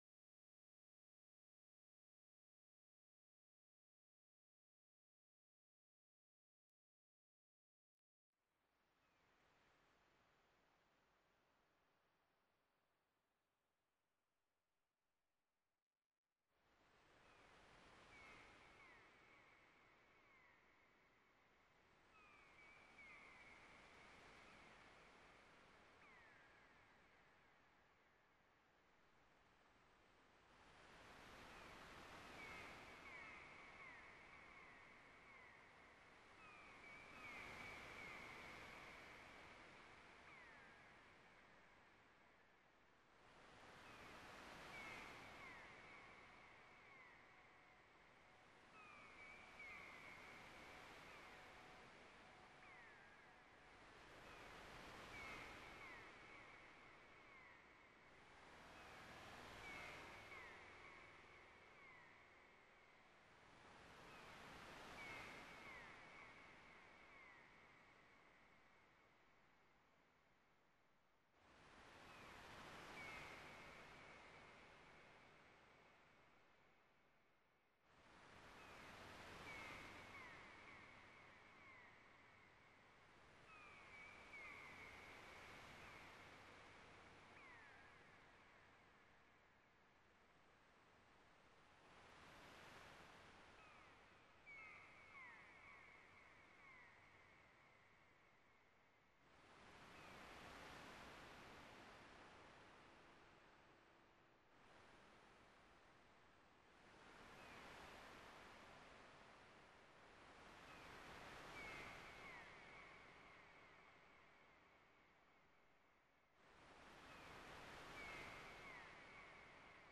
Beach - sample - this is the first 5 minutes of digitally re-created sounds of the beach.
beach_5.mp3